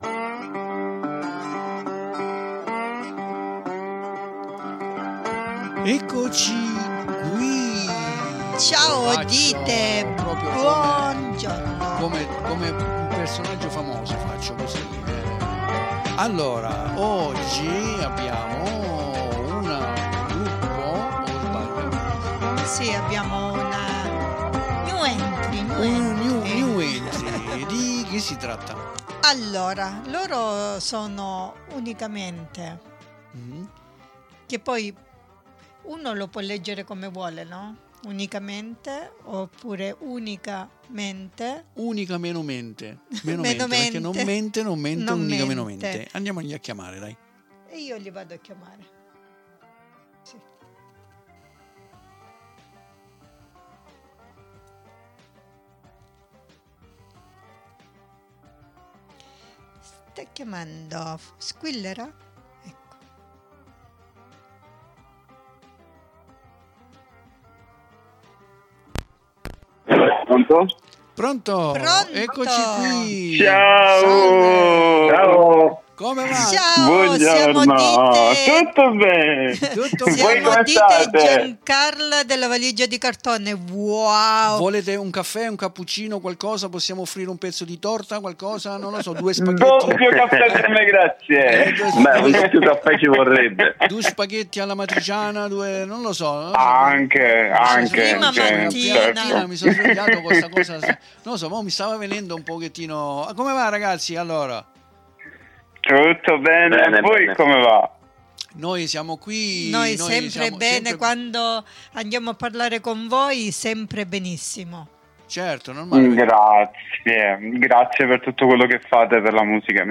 IO NON VI SVELO ALTRO E VI LASCIO A QUESTA INTERVISTA CONDIVISA QUI IN DESCRIZIONE, CON QUESTI DUE RAGAZZI FORMIDABILI!